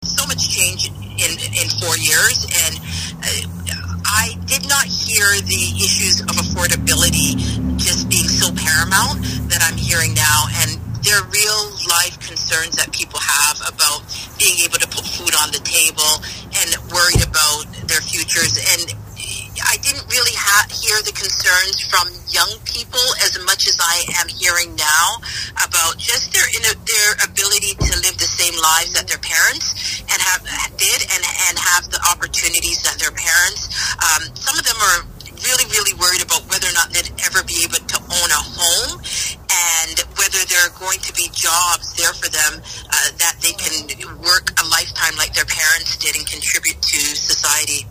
Speaking with the media over the phone, she was thrilled to be given the chance to work for the people of the riding for four more years.